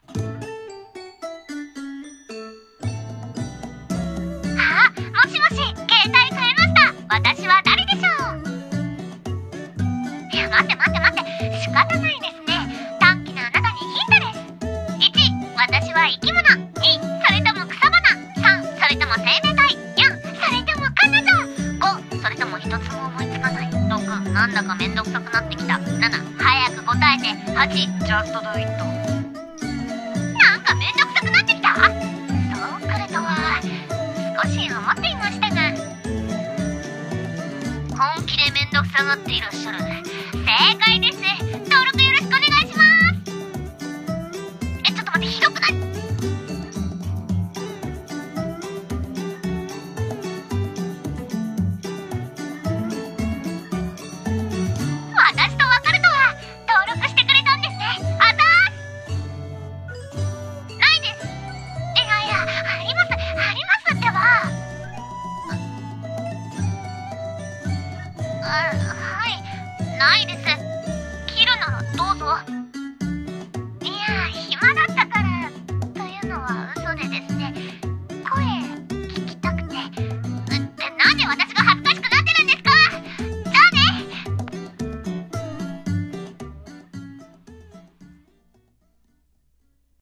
【声劇台本】いたずら電話w